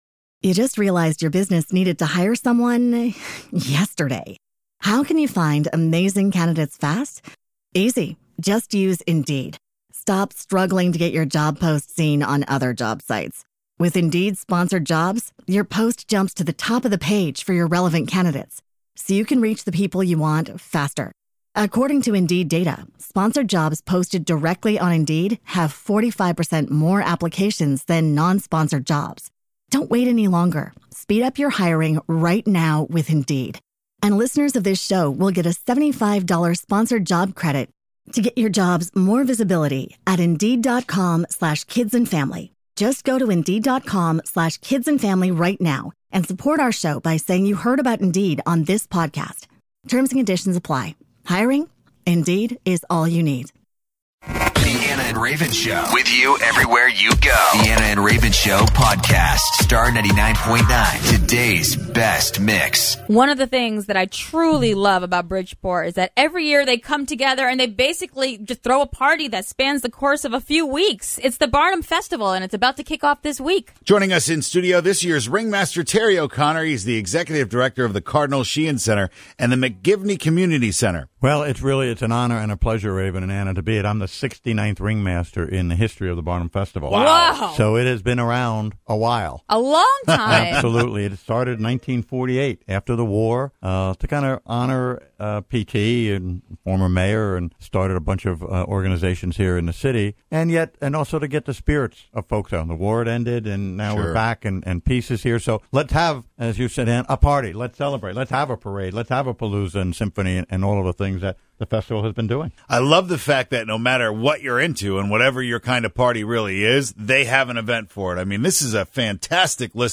joined us in studio today to talk about this year’s list of events planned.